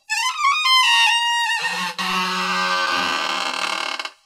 • creepy creaking door sound effect.wav
A very creepy door squeaking/creaking sound effect recorded in one of my back rooms, just before greasing my old wooden door. Captured with a TASCAM DR 40
creepy_creaking_door_sound_effect_uZE.wav